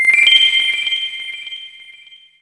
ui_gain_level.wav